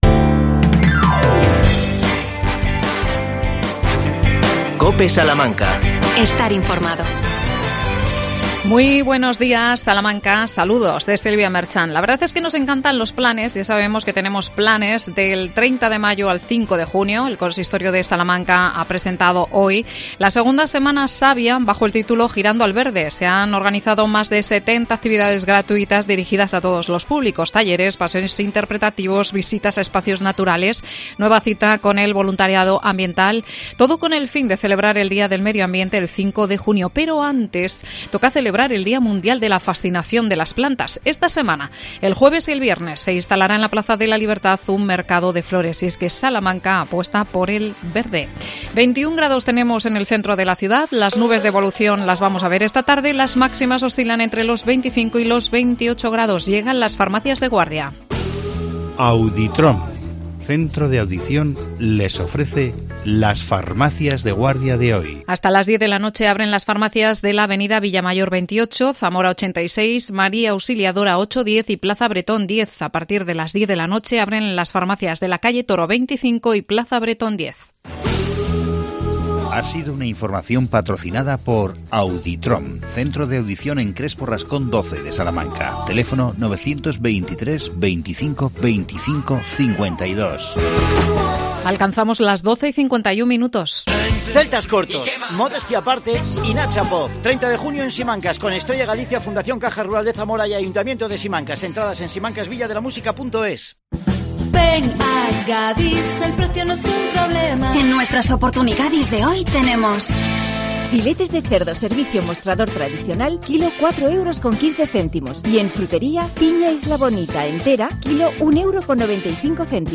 AUDIO: Entrevista a Fernando Carabias, concejal de Tráfico. El tema: el regreso de la Unidad de Policía Turística.